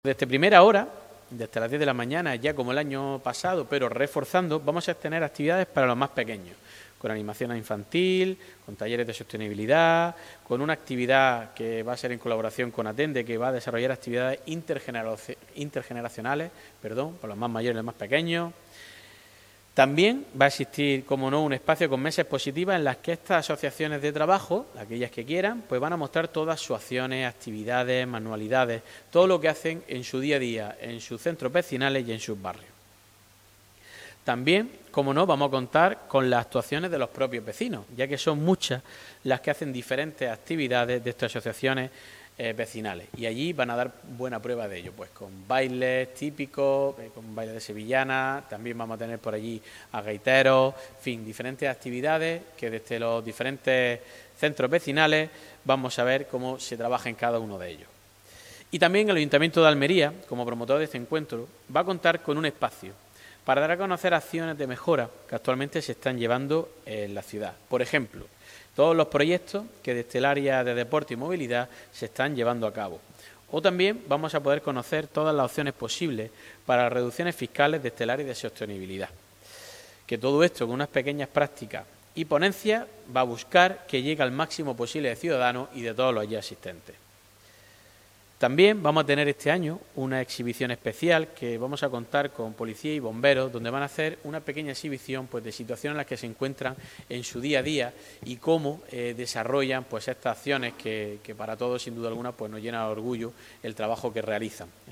El concejal de Integración, Participación y Distritos, Oscar Bleda, presenta el programa de actividades de este año, “una oportunidad para fortalecer los lazos de colaboración entre la ciudadanía y el Ayuntamiento”